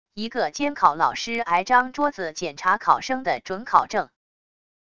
一个监考老师挨张桌子检查考生的准考证wav音频